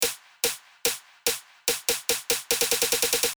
やり方はまずスネアを8分→16分→32分というように打ち込みます。
打ち込めると以下のような音になります。
snare-build-up.mp3